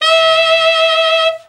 BRA_TENOR OB  22.wav